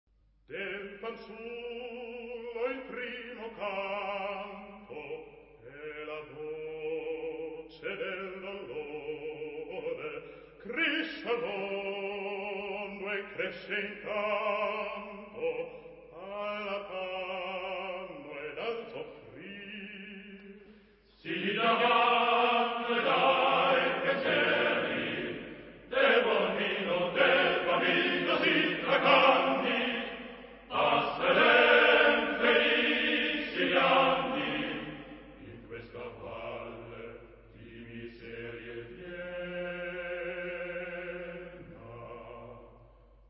Genre-Style-Forme : Romantique ; Profane
Type de choeur : TTBB  (4 voix égales d'hommes )
Solistes : Baryton (1)  (1 soliste(s))
Tonalité : si bémol majeur